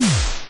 knockback.ogg